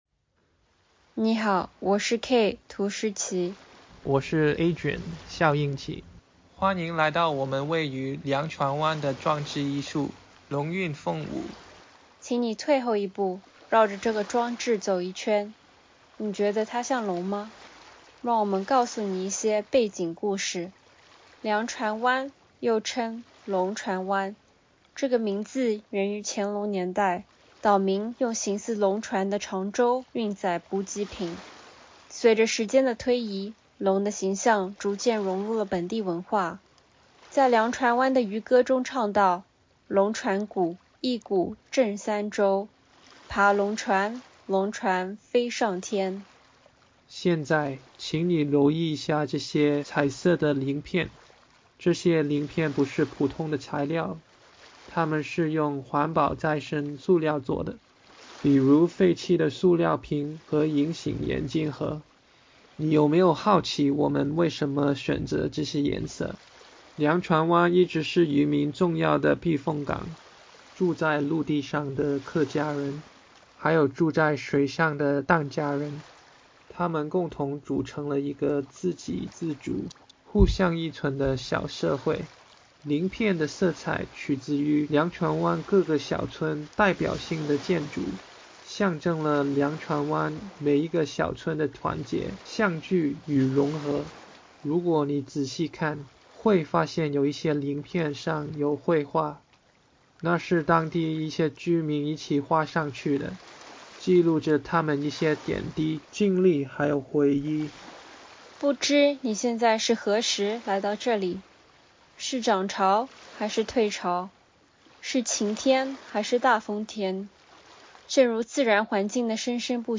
《龙韵风舞》— 艺术家原声介绍按此阅读原声介绍文字稿